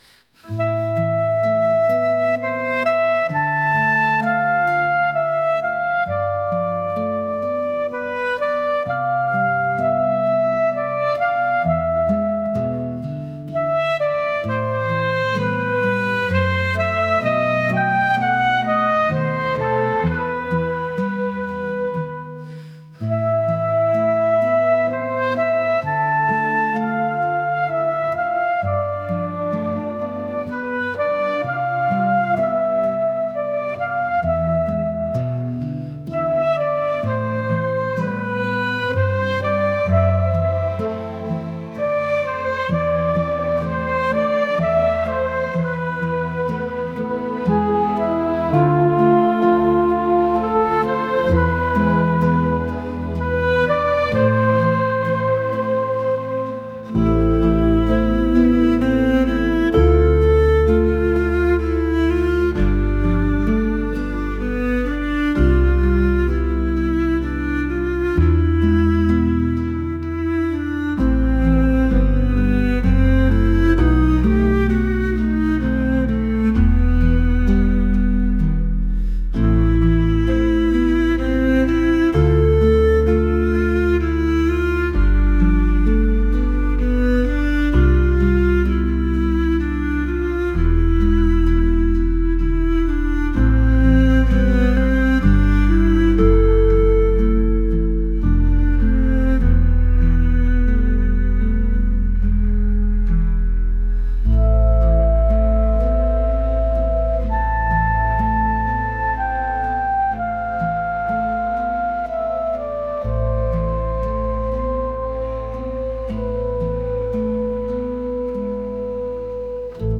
pop | acoustic